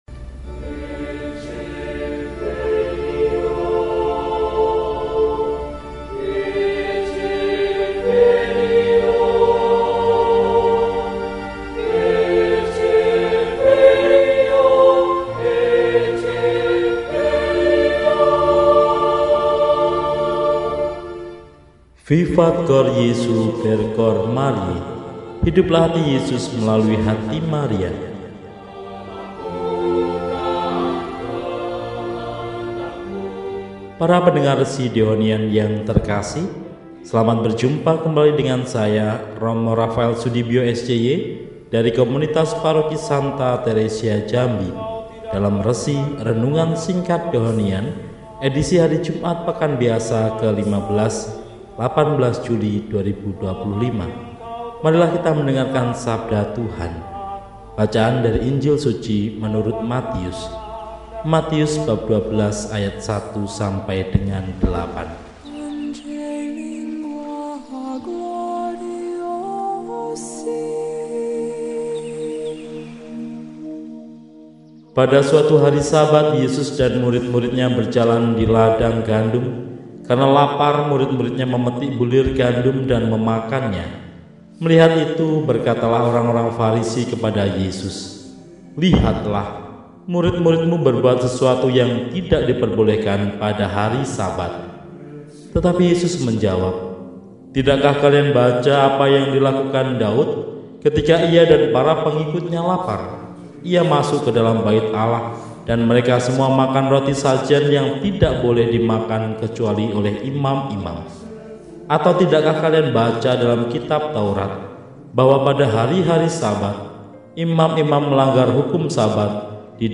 Jumat, 18 Juli 2025 – Hari Biasa Pekan XV – RESI (Renungan Singkat) DEHONIAN